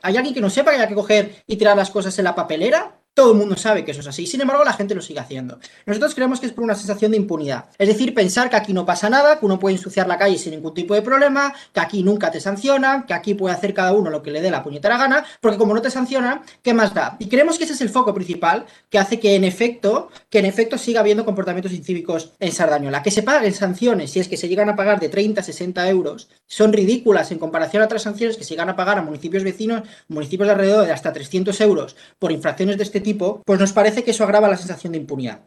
Gorka Samaniego, regidor C's